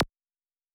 Closed Hats
pcp_lazer.wav